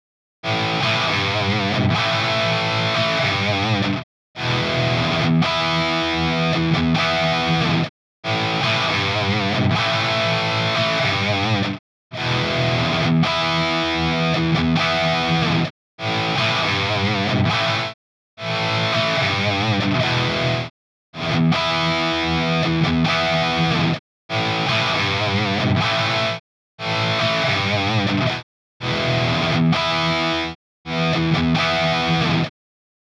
так чем? 11 отрезков в примере ниже. Опишите где звучит реальный амп, а где звучит kemper во всех 11 отрезках.